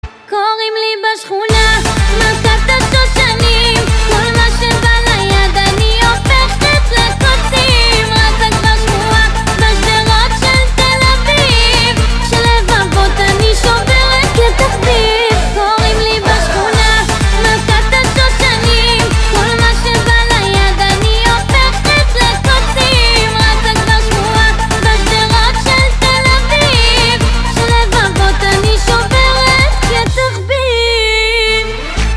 • Качество: 256, Stereo
веселые
израильская музыка
Веселая израильская песенка)))